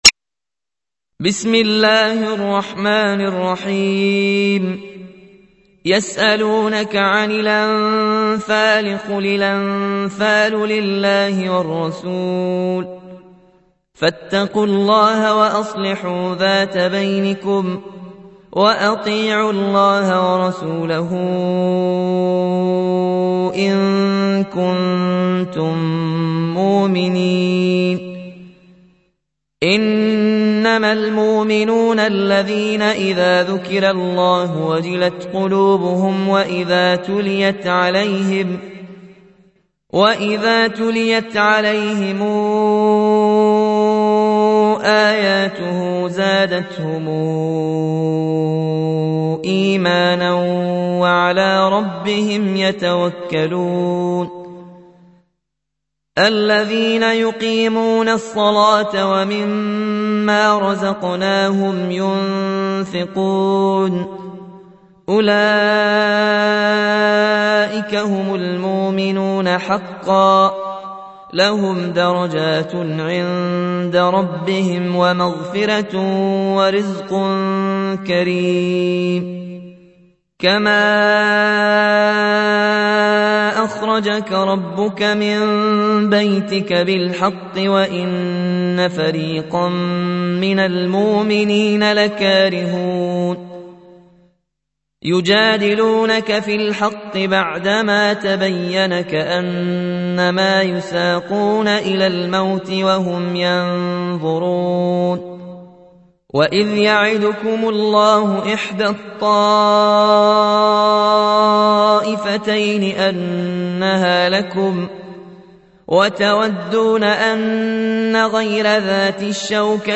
تحميل : 8. سورة الأنفال / القارئ ياسين الجزائري / القرآن الكريم / موقع يا حسين